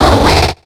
Cri de Goinfrex dans Pokémon X et Y.